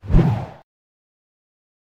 swoosh.wav